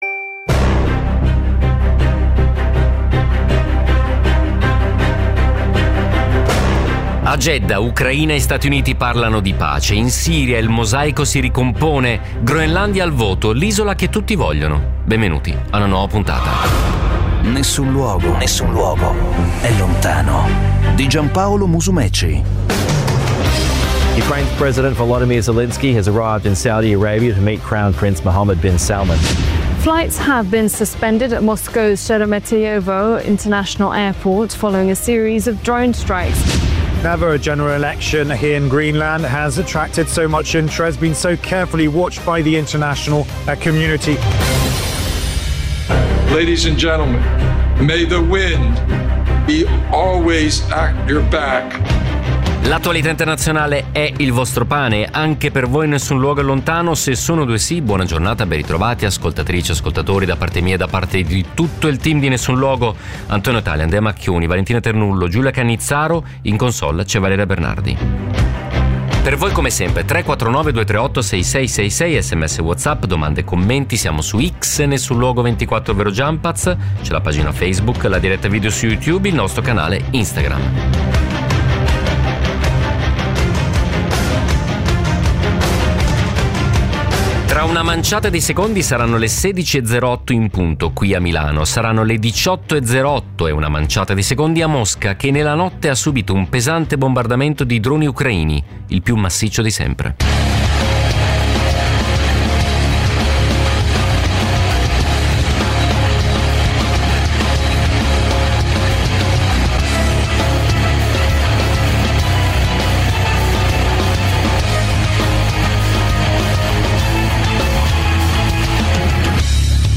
… continue reading 751 에피소드 # News Talk # Notizie # Radio 24